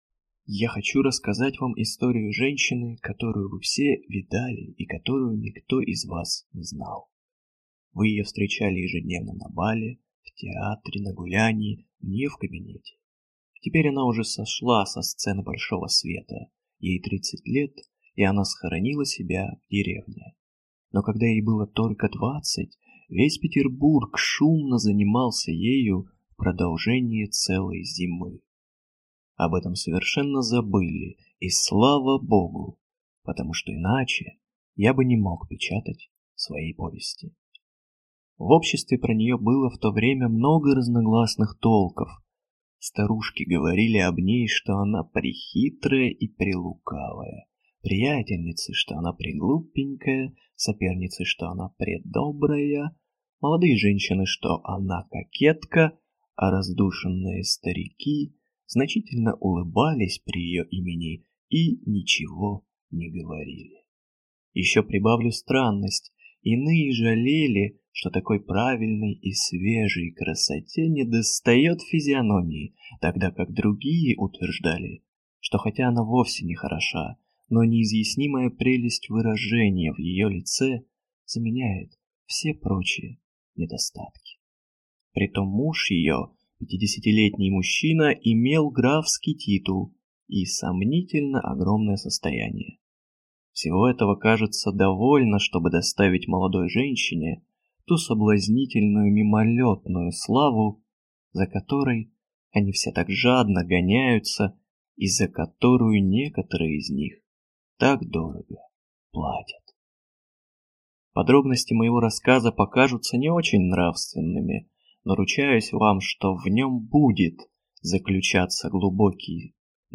Аудиокнига Я хочу рассказать вам | Библиотека аудиокниг